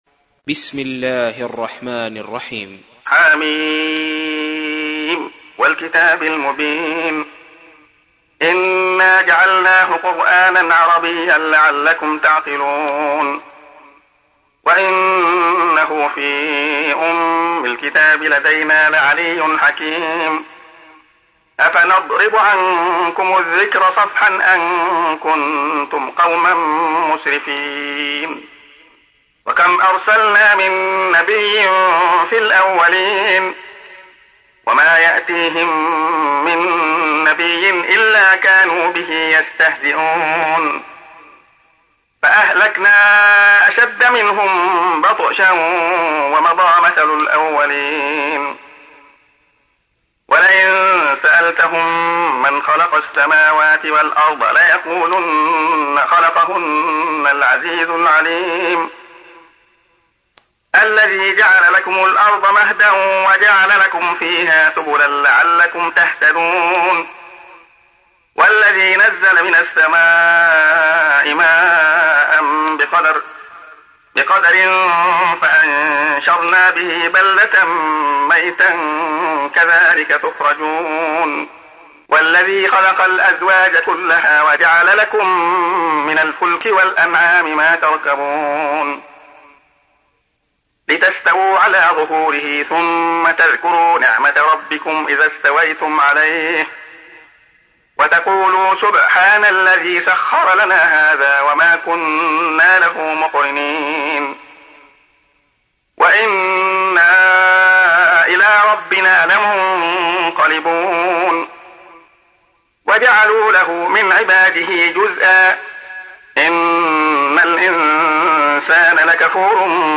تحميل : 43. سورة الزخرف / القارئ عبد الله خياط / القرآن الكريم / موقع يا حسين